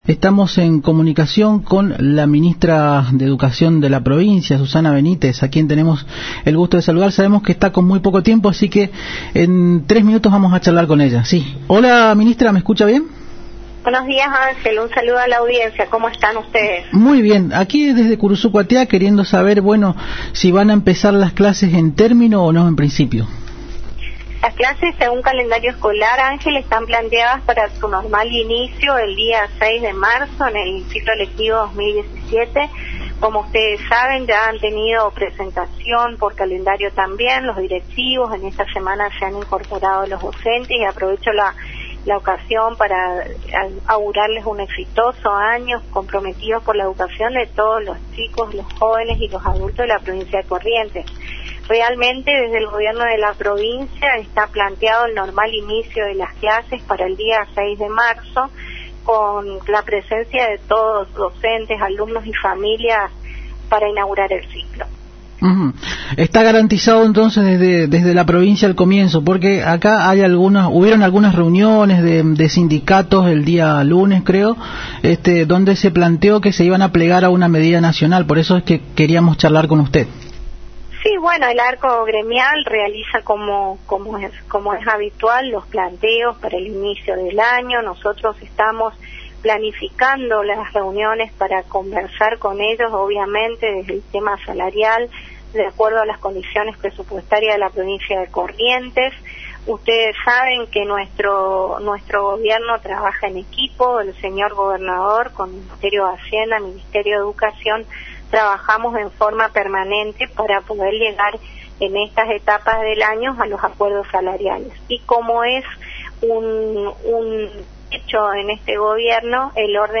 La ministra de educación de la provincia de Corrientes, Susana Benítez indicó en el aire radial de la AM 970 Radio Guarani que "las clases, según calendario escolar, están planteadas para su normal inicio, el seis de marzo en el ciclo lectivo 2017".
Susana Benitez - Ministra de Educacion Pcia..mp3